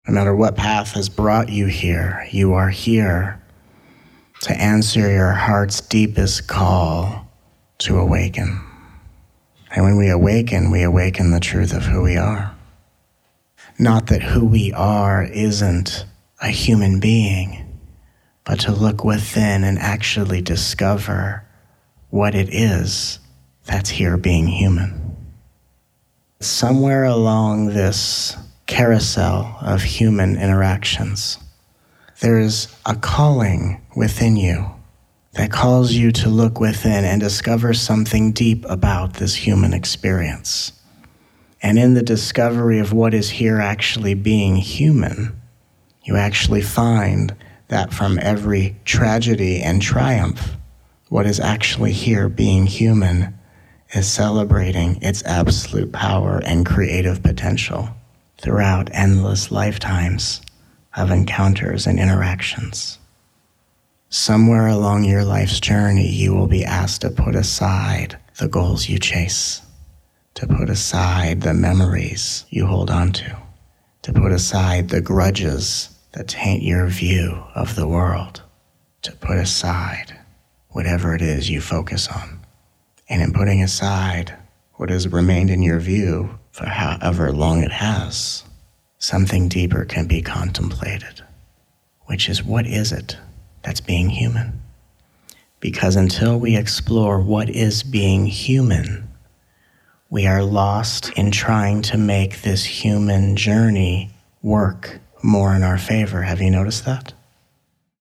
This special package of teachings includes 7 hours of energetically-fueled downloads, this never-before-released collection of teachings clarifies all aspects of the spiritual journey with humor, compassion, and ease, including: